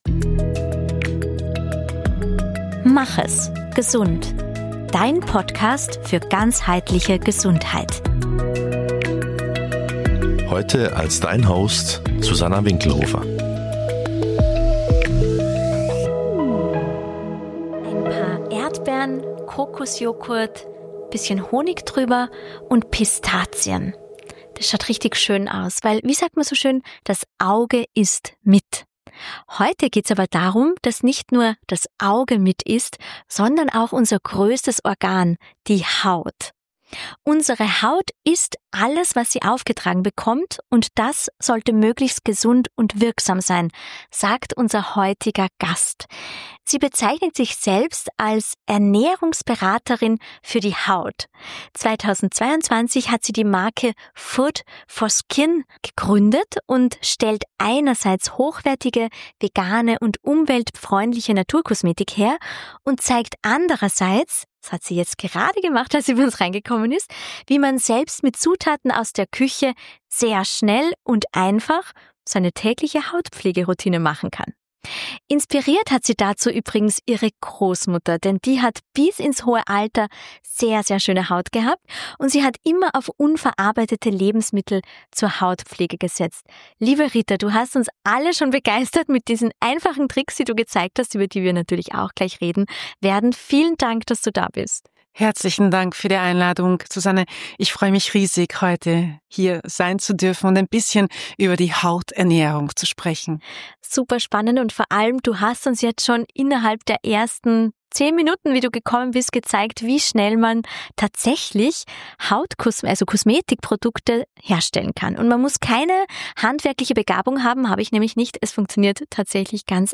Ein Gespräch über Selbstfürsorge, Hauthunger und den Mut, Kosmetik neu zu denken.